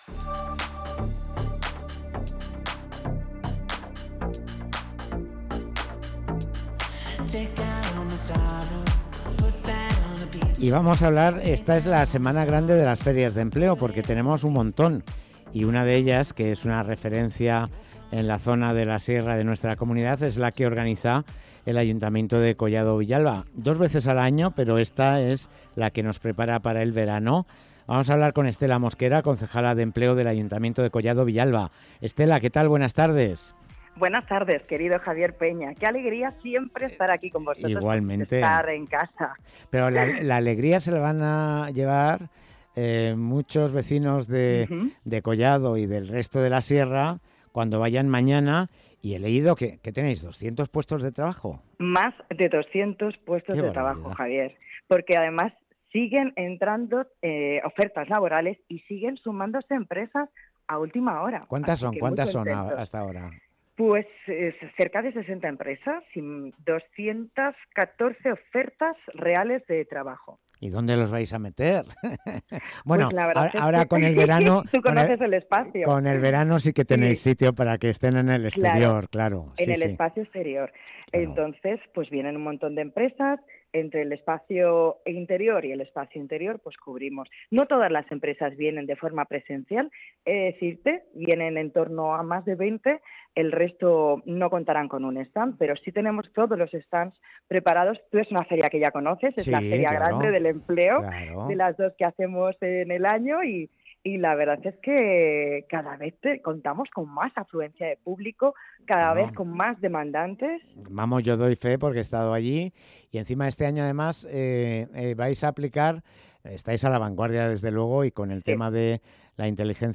Entrevista a Estela Mosquera, concejala de Empleo de Collado Villalba